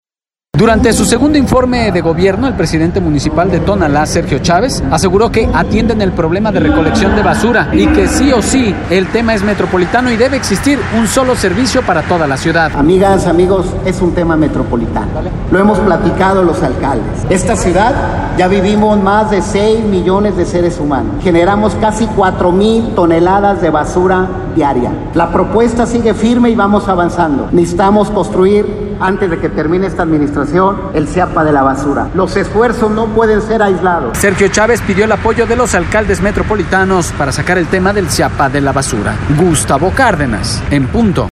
Durante su segundo Informe de Gobierno, el presidente municipal de Tonalá, Sergio Cávez aseguró que atienden el problema de recolección de basura y que sí o sí el tema es metropolitano y debe existir un sólo servicio para toda la ciudad.